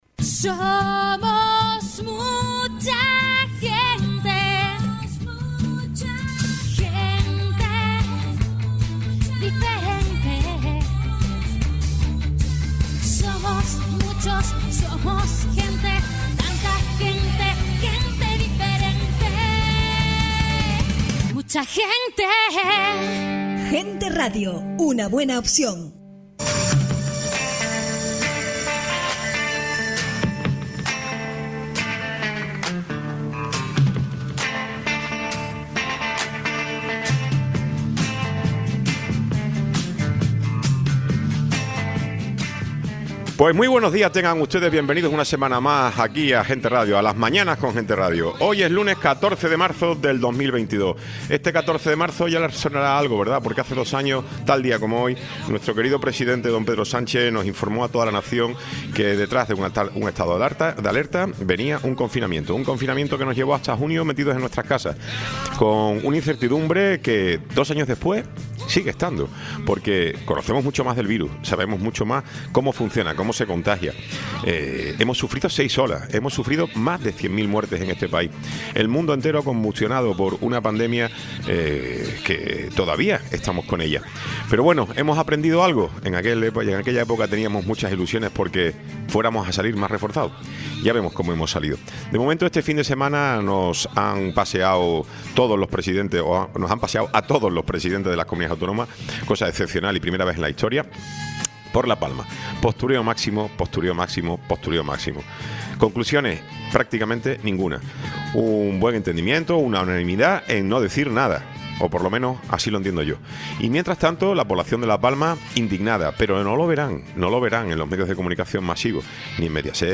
Tiempo de entrevista